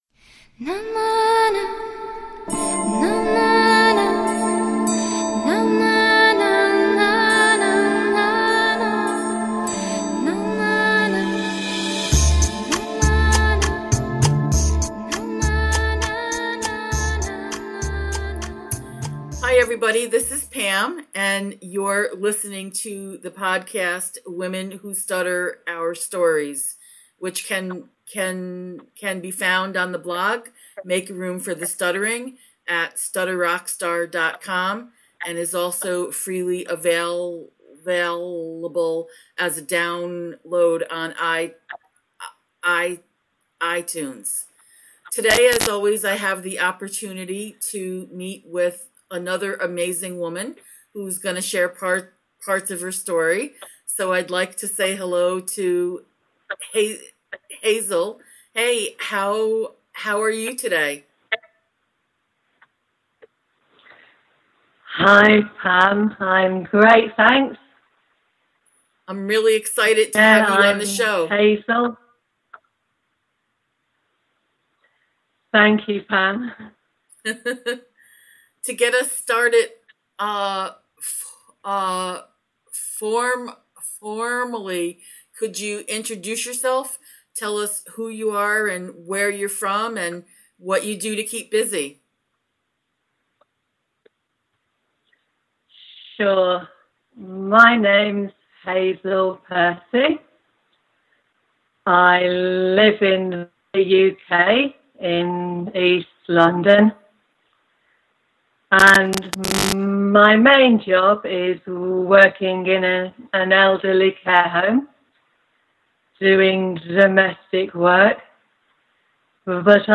The podcast safe music used in today’s episode is credited to ccMixter . Producer Note: Yes, there is a lot of static in this episode. We had a transatlantic internet connection and it was not always the best.